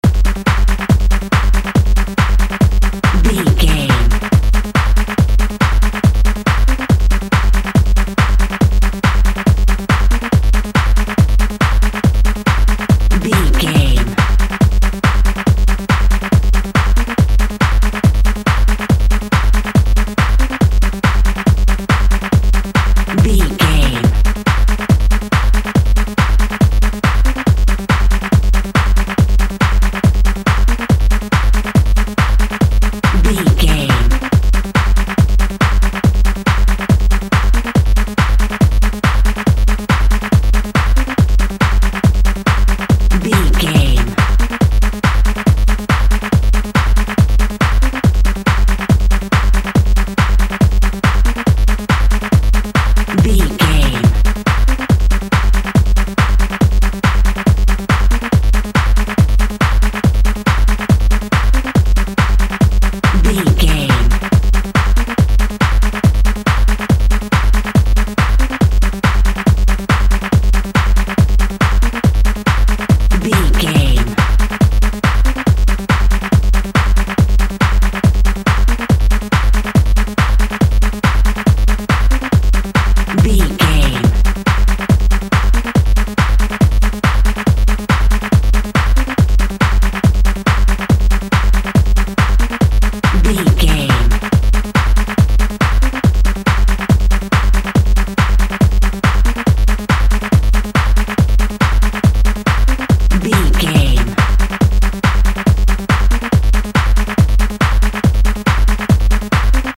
Aeolian/Minor
Fast
energetic
high tech
hypnotic
industrial
frantic
drum machine
synthesiser
synth lead
synth bass